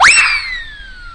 • 哨子
whistle.wav